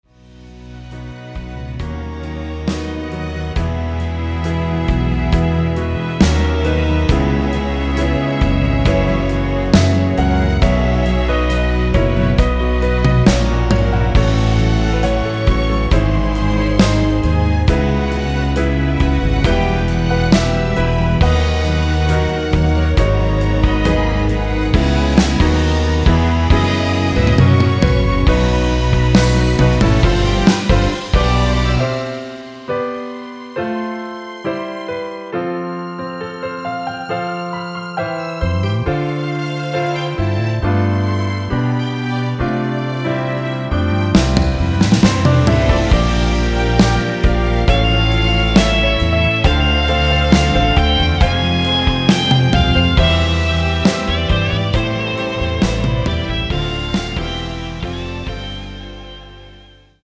1절후 후렴으로 진행되게 편곡된 MR 입니다.
원키(1절+후렴)으로 편곡된 MR입니다.(미리듣기및 가사 참조)
앞부분30초, 뒷부분30초씩 편집해서 올려 드리고 있습니다.